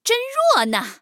黑豹开火语音2.OGG